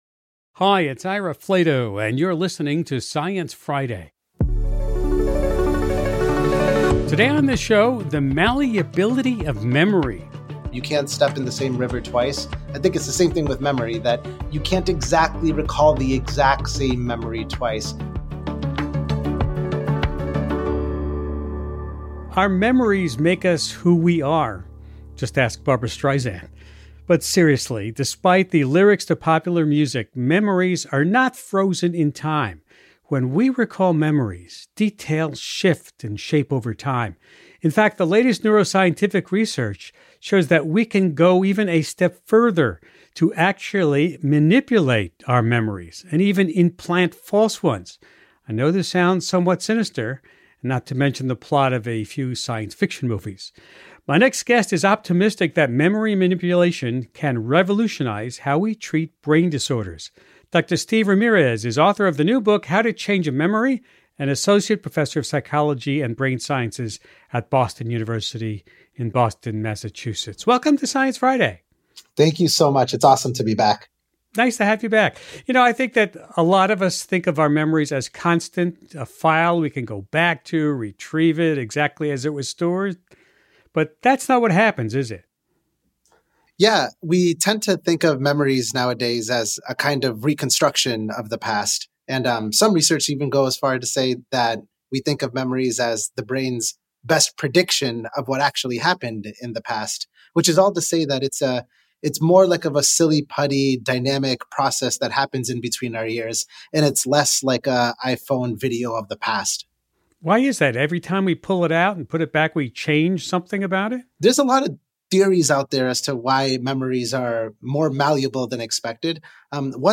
Host Ira Flatow